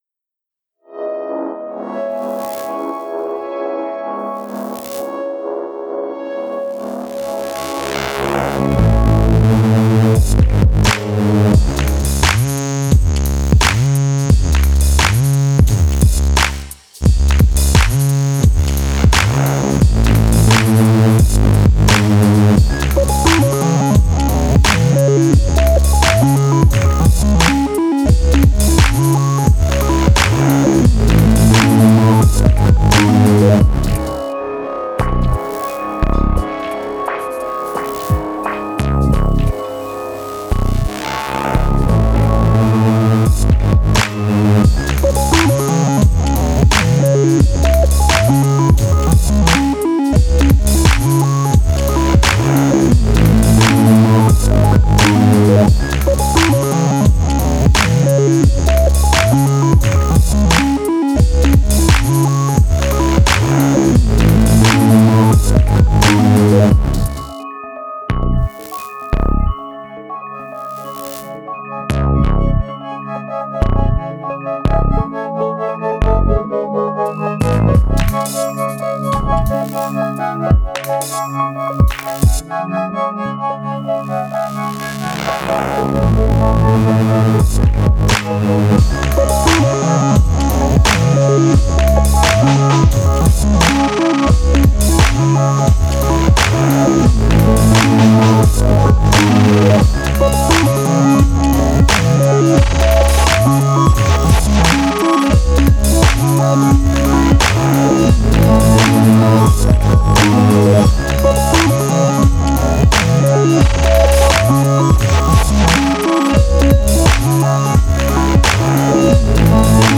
Bass-heavy electronica with street attitude.